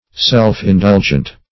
Self-indulgent \Self`-in*dul"gent\, a.